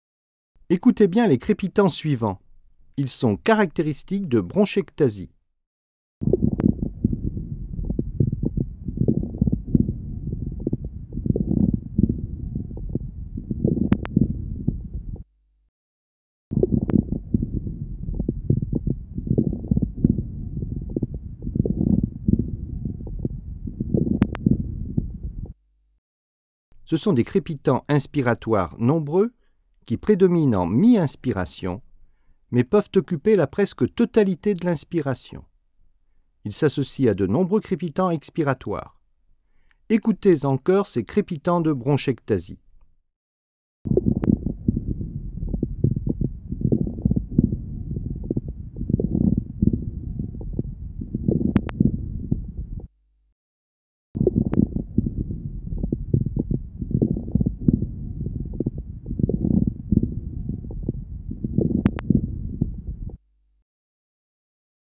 écouter les bruits respiratoires